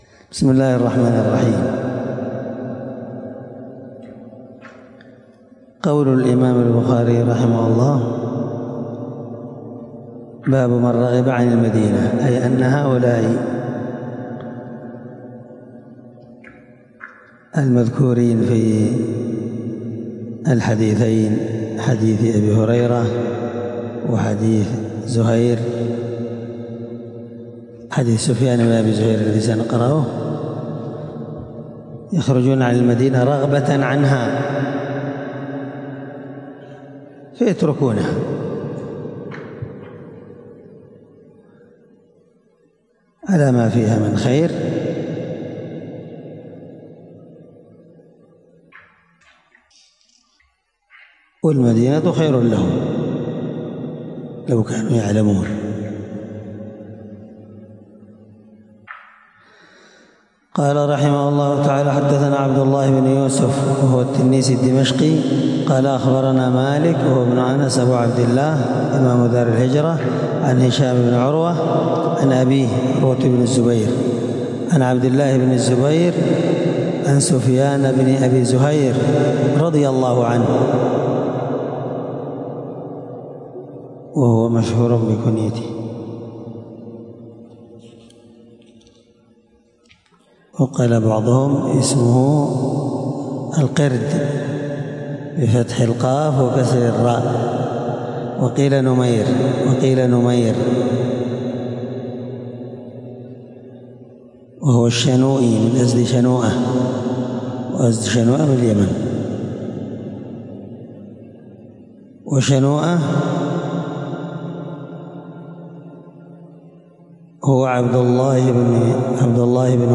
الدرس6من شرح كتاب فضائل المدينة حديث رقم(1874-1875 )من صحيح البخاري